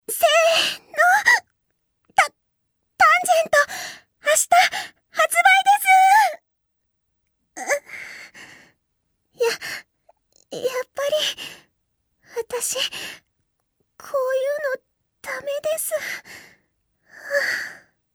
「tan.-タンジェント-」発売日前日ボイス　-砂城編-